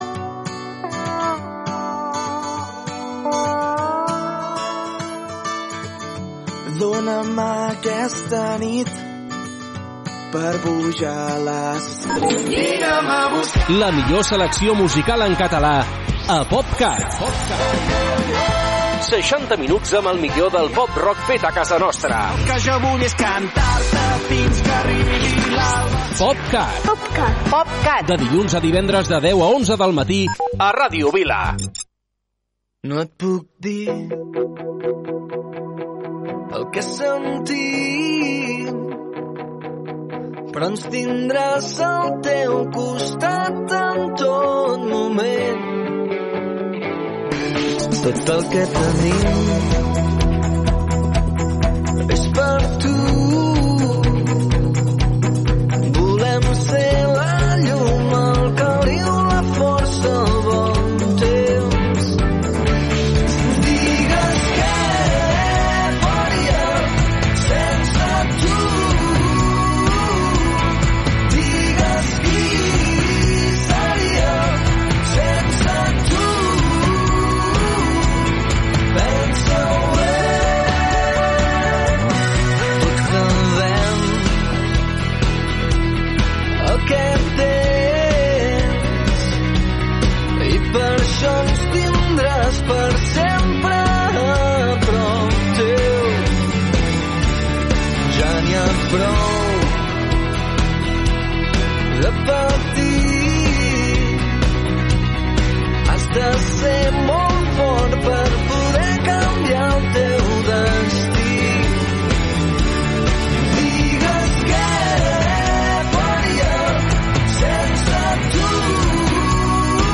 60 minuts de la millor música feta a casa nostra.